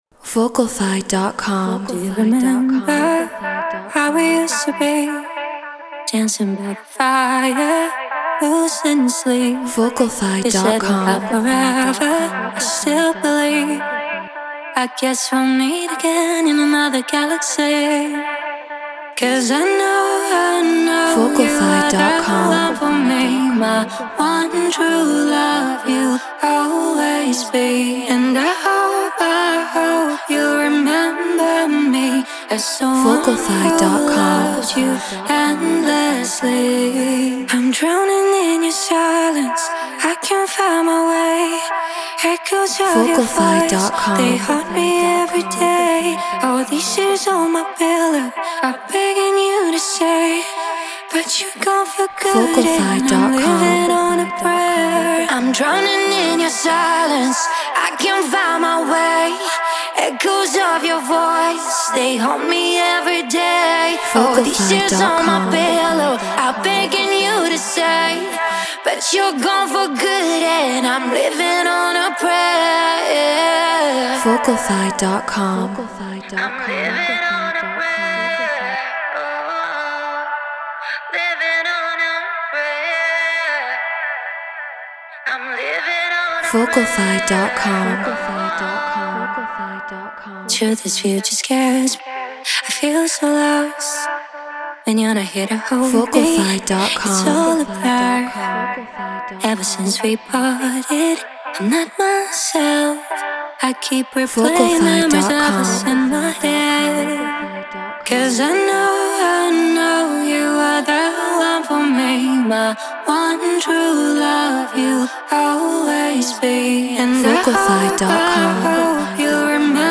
Pop 108 BPM Cmaj
Treated Room